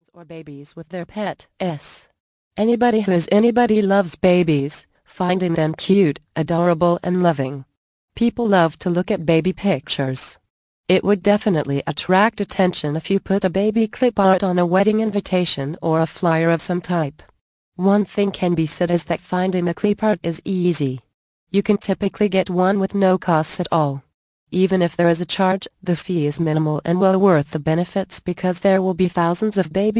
The Ultimate Baby Shower Guide mp3 audio book Vol. 6